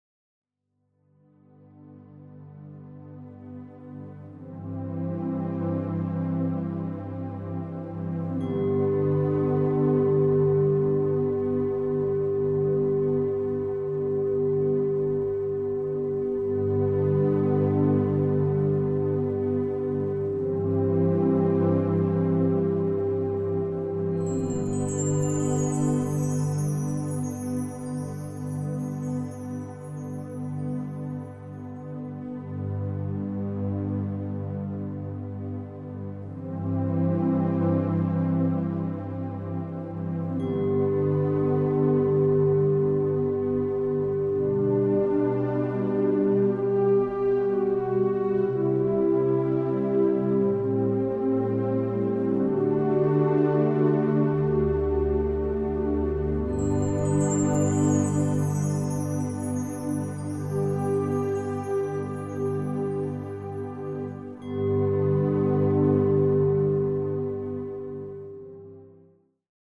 passend abgestimmt auf die 9 Solfeggio-Frequenzen.